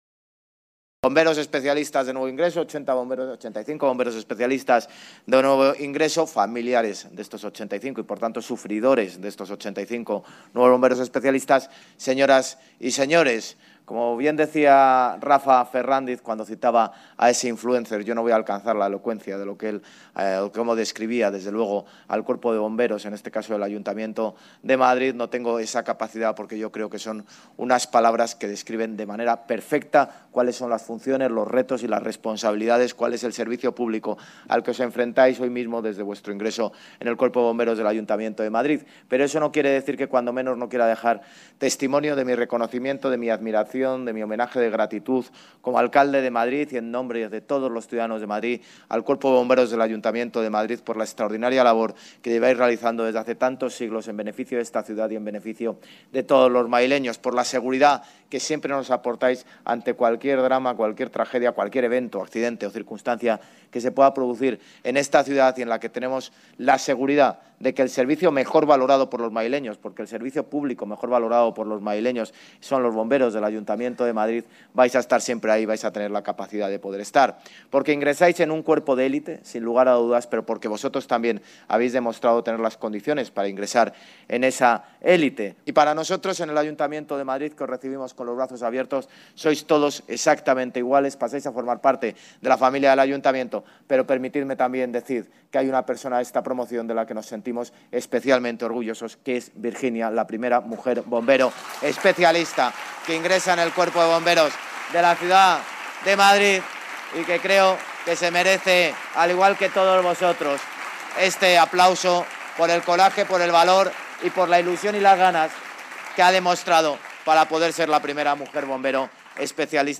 Nueva ventana:Declaraciones del alcalde de Madrid, José Luis Martínez-Almeida